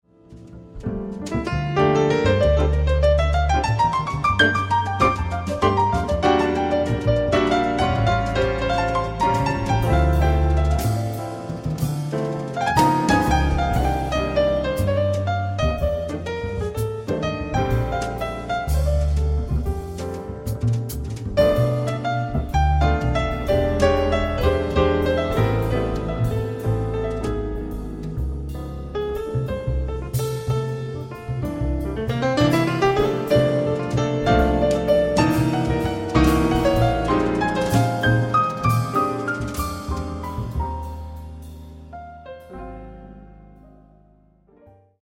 四、採用爵士樂中最受人喜愛的 鋼琴、鼓、貝斯 三重奏編制，演奏受人喜愛的旋律